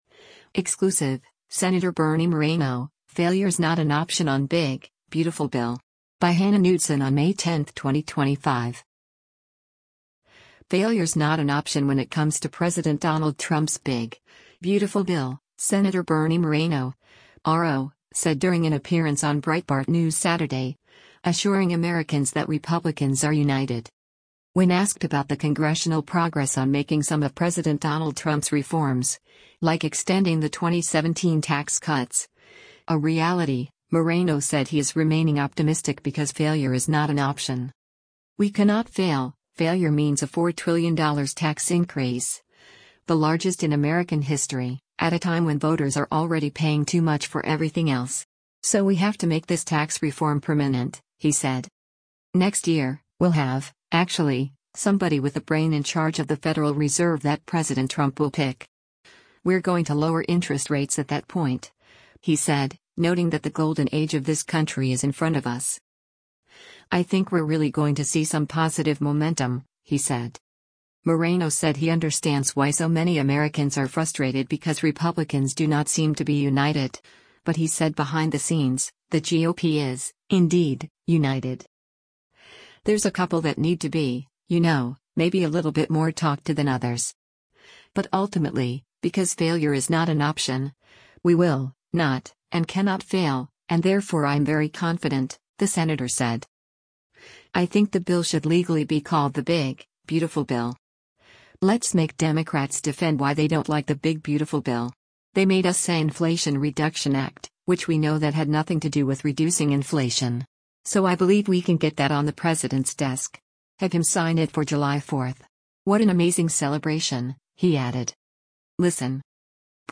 “Failure’s not an option” when it comes to President Donald Trump’s “big, beautiful bill,” Sen. Bernie Moreno (R-OH) said during an appearance on Breitbart News Saturday, assuring Americans that Republicans are united.
Breitbart News Saturday airs on SiriusXM Patriot 125 from 10:00 a.m. to 1:00 p.m. Eastern.